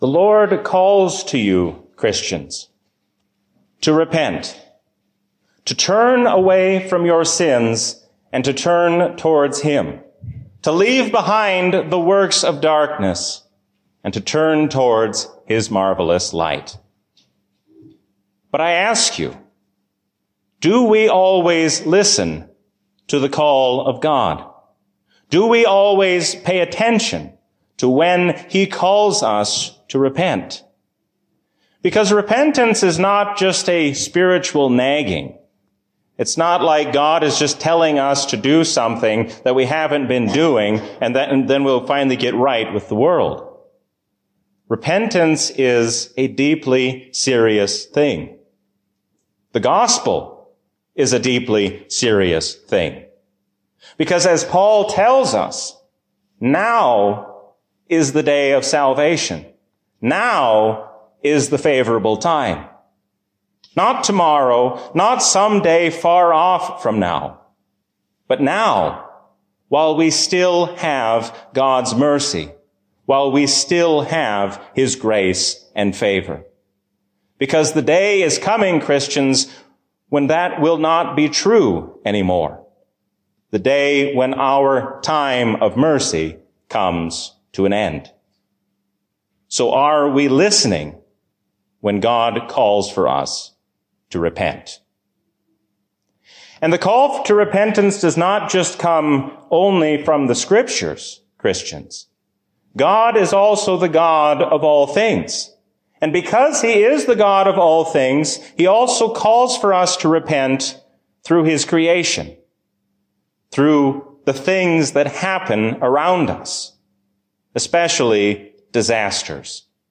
A sermon from the season "Trinity 2024." There is no reason to worry about Tuesday or any day to come when we remember that the Lord reigns as King forever.